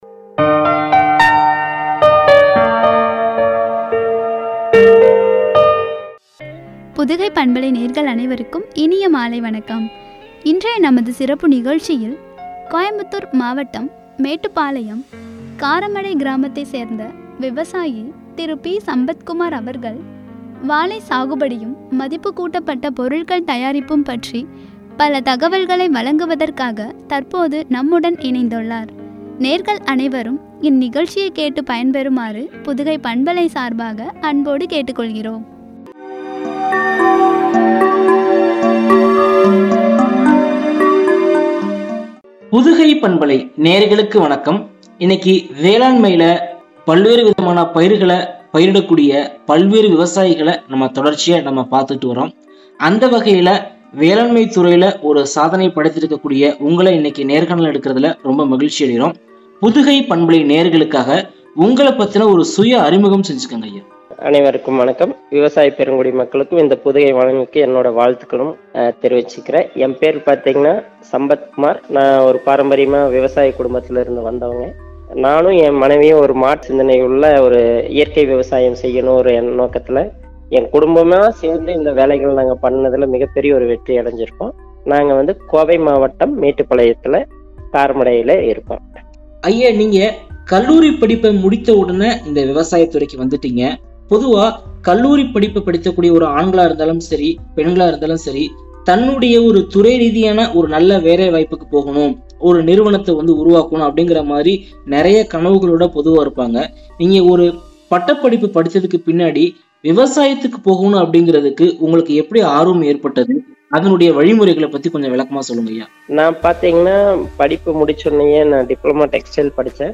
மதிப்புக்கூட்டப்பட்ட பொருட்கள் தயாரிப்பும்” குறித்து வழங்கிய உரையாடல்.